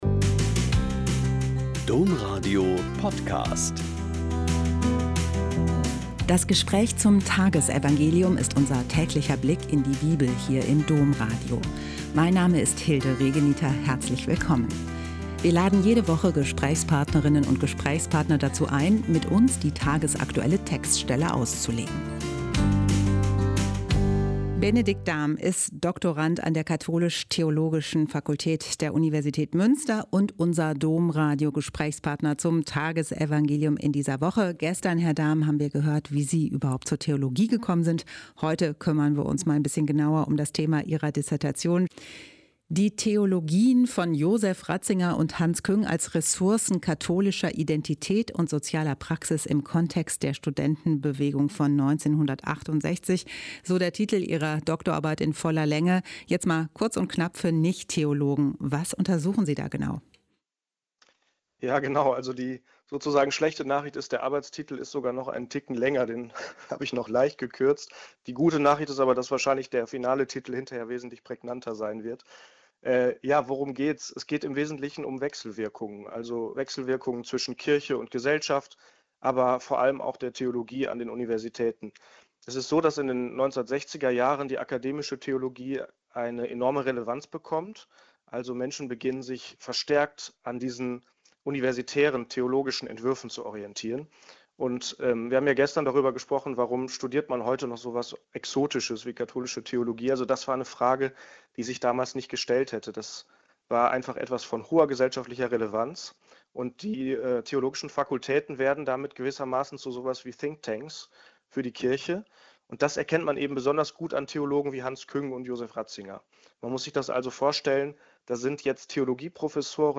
Joh 11,19-27 - Gespräch